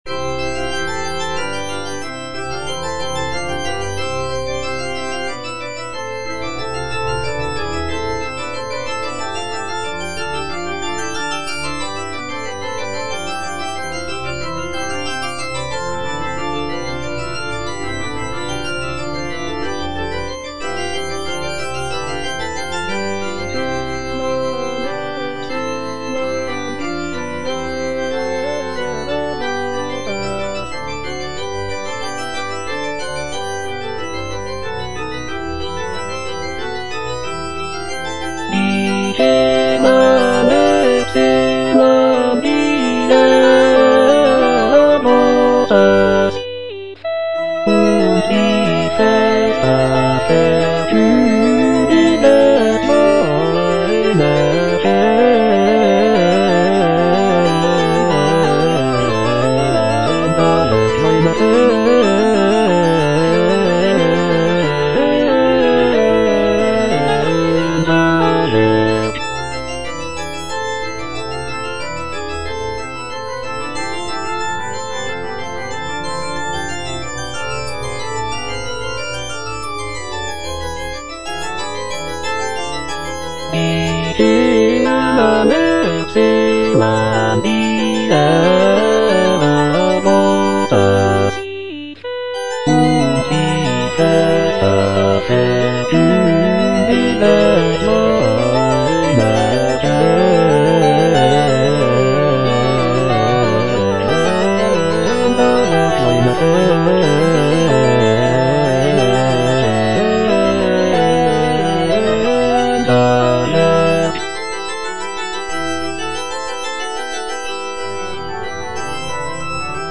Choralplayer playing Cantata
The work features intricate choral writing, beautiful melodies, and rich orchestration, showcasing Bach's mastery of baroque music composition."